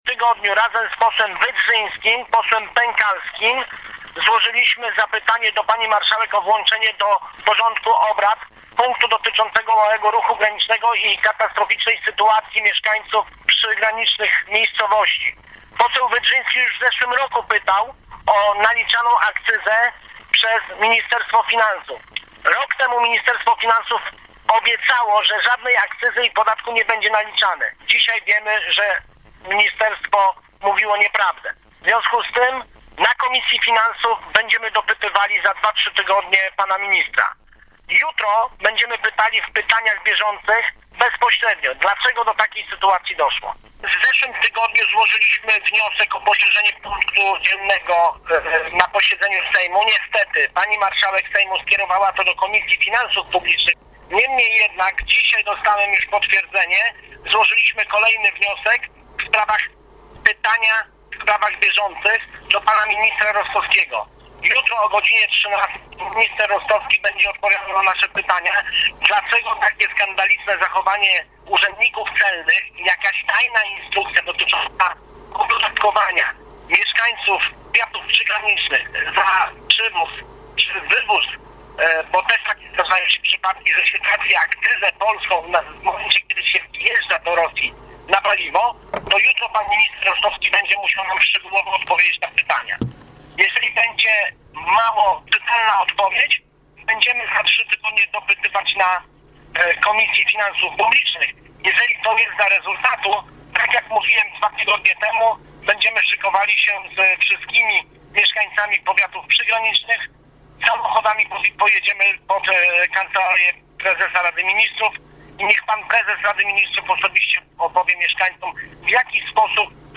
mówi poseł Tomasz Makowski (Ruch Palikota)